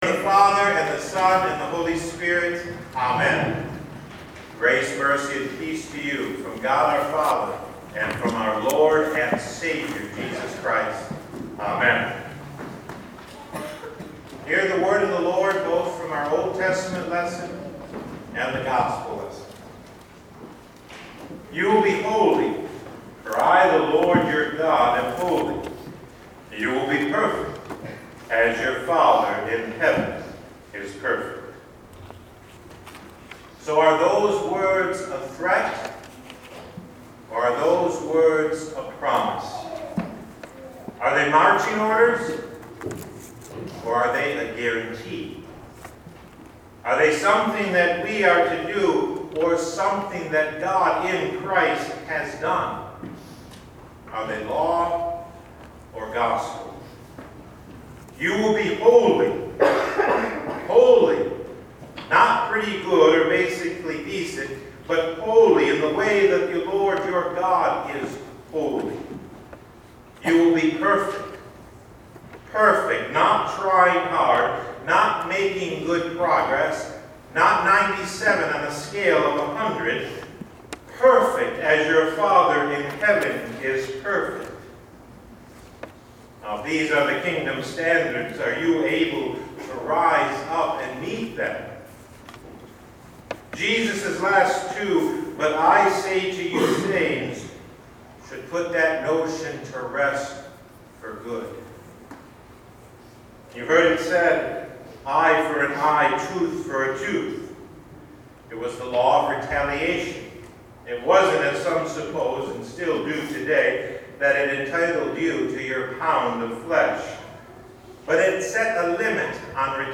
Past Sermons (Audio) - St. Luke Lutheran Church